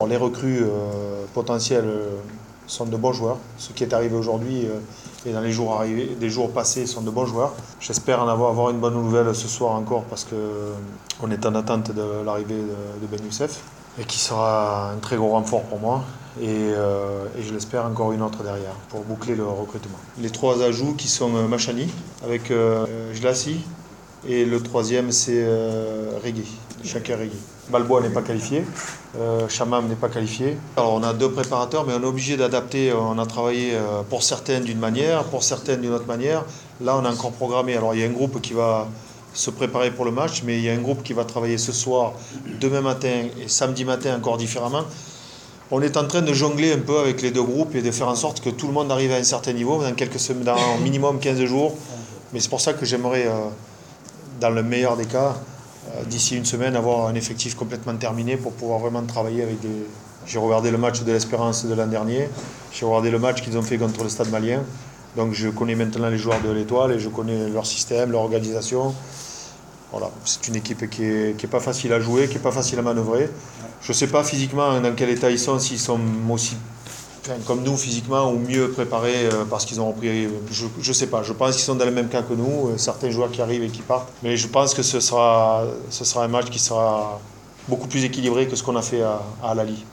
اعلن مدرب الترجي الرياضي جوزيه انيغو خلال الندوة الصحفية اضافة الثلاثي علي المشاني, شاكر الرقيعي و الياس الجلاصي للقائمة الافريقية كما اكد ان فخر الدين بن يوسف سيكون على ذمة الترجي ولن يلعب مباراة النجم .